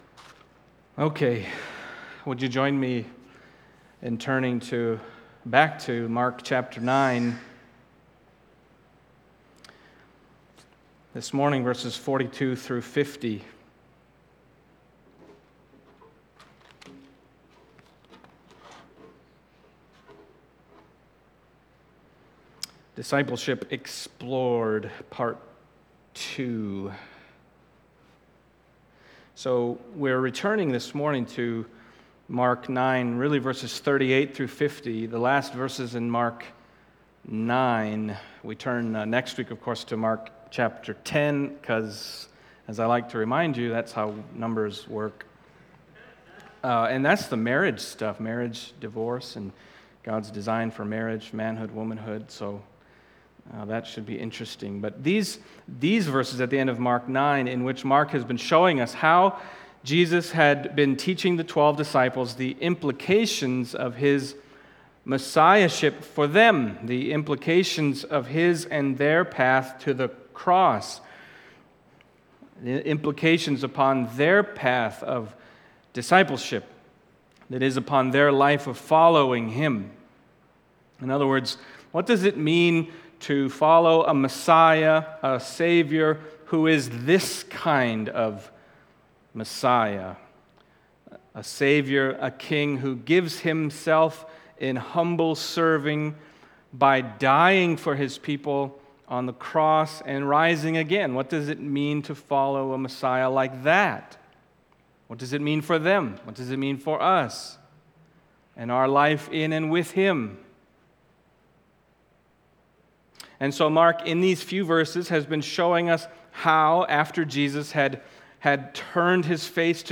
Mark Passage: Mark 9:42-50 Service Type: Sunday Morning Mark 9:42-50 « Discipleship Explored